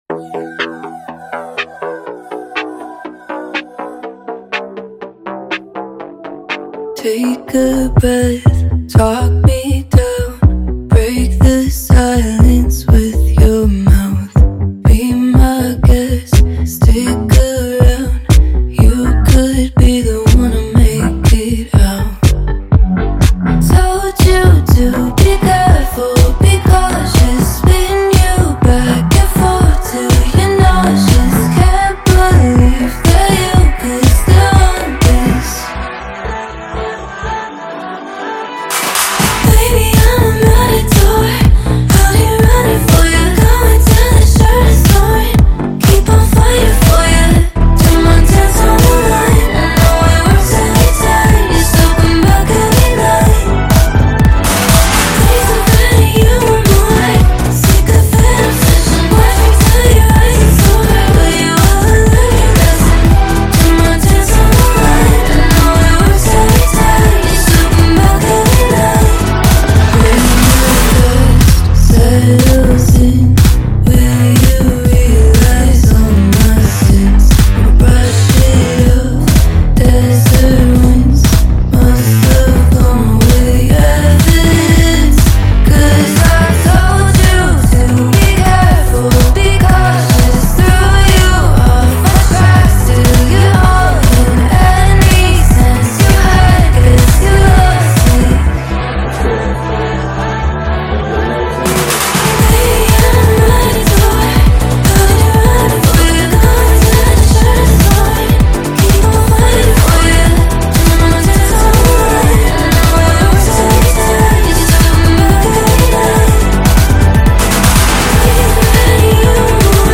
альтернативный поп